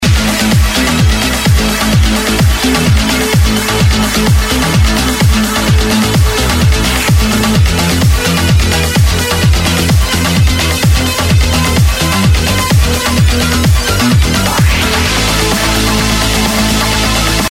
На слух это особенно очень заметно в плаках, а так же в некоторых басах.
Вложения sound_lead.mp3 sound_lead.mp3 277,6 KB · Просмотры: 299